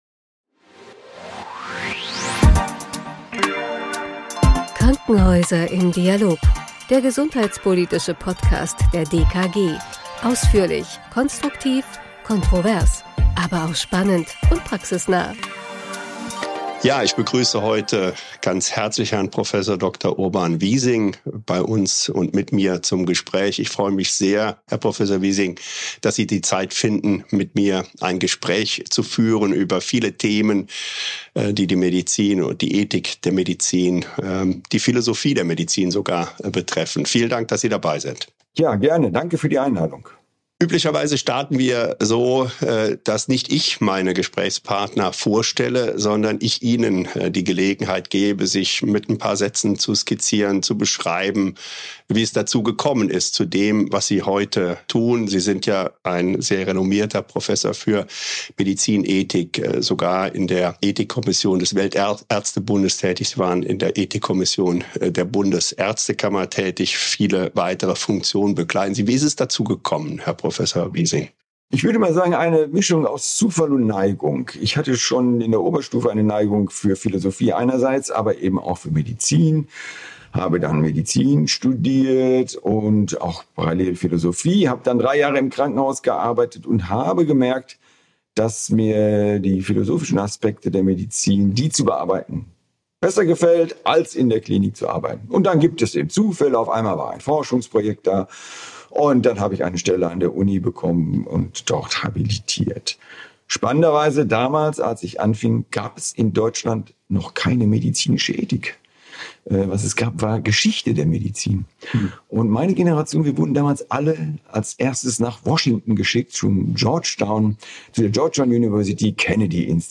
Im Gespräch dreht sich alles um die Ethik in der Medizin – vor dem Hintergrund einer älter werdenden Gesellschaft, begrenzter Ressourcen und hochmoderner Therapien. Besondere Schwerpunkte sind die Transplantationsmedizin, die Corona-Pandemie, Prävention und die medizinische Versorgung am Lebensende.